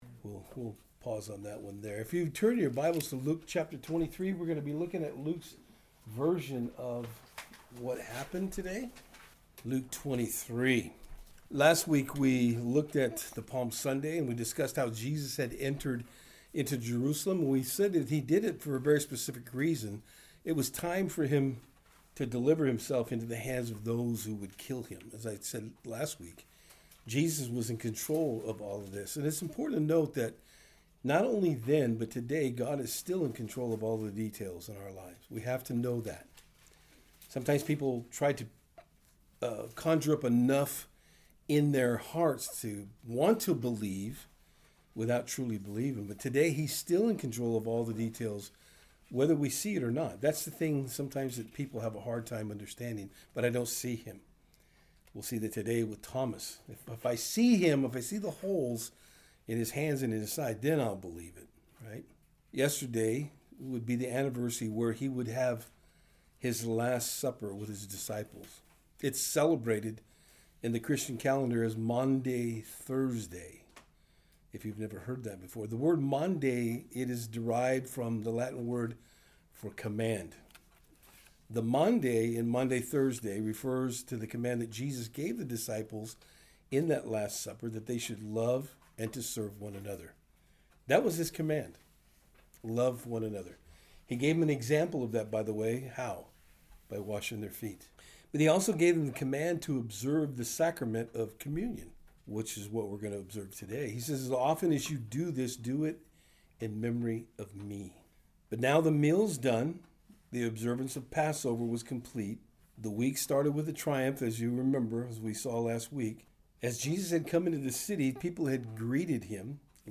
Service Type: Good Friday Message